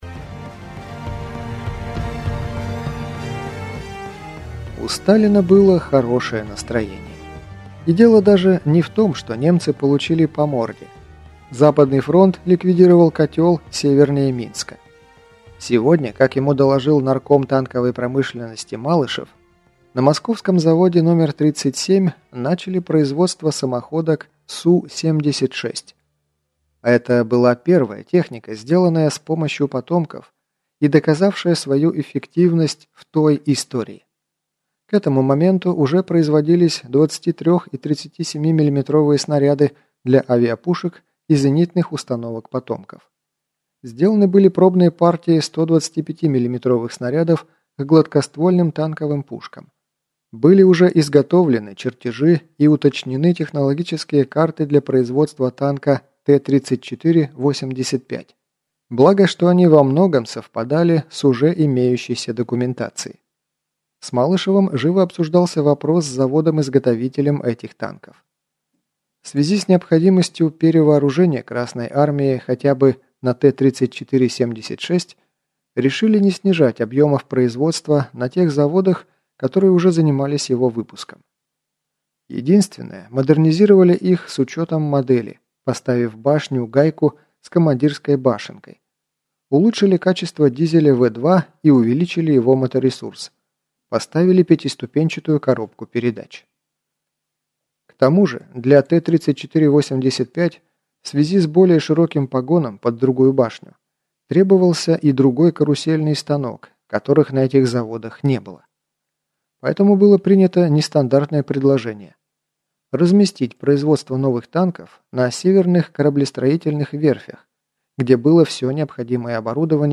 Аудиокнига Воскресное утро | Библиотека аудиокниг